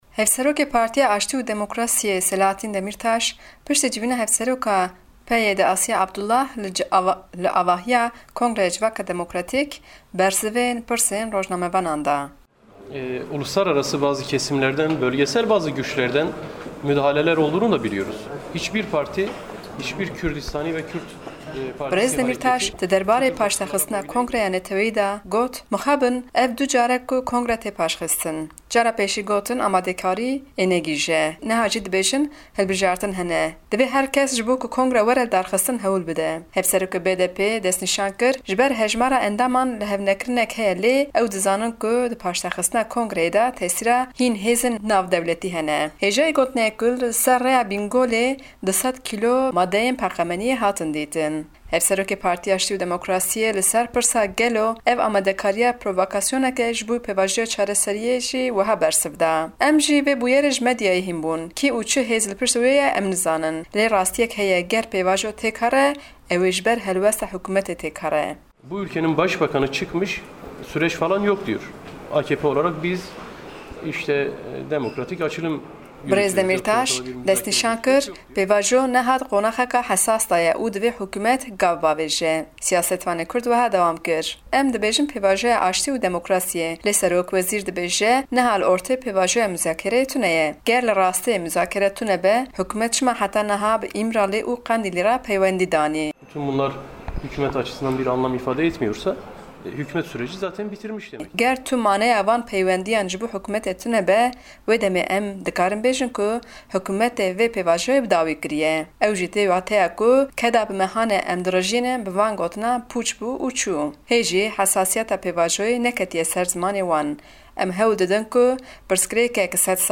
Raport